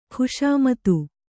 ♪ khuṣāmatu